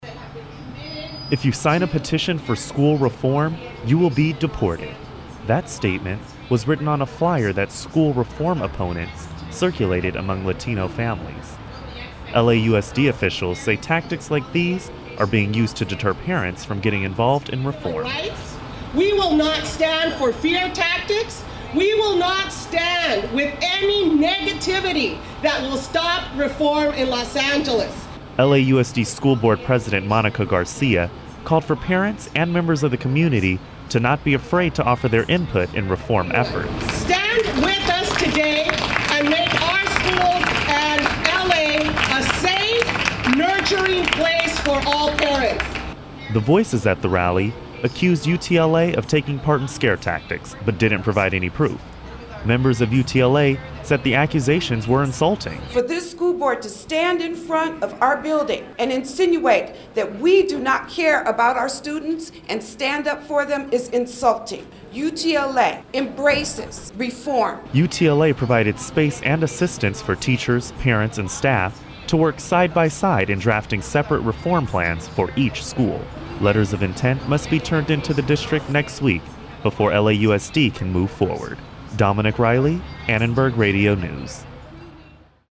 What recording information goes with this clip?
A rally today outside of United Teachers of Los Angeles headquarters showed how heated the debate over these schools is becoming.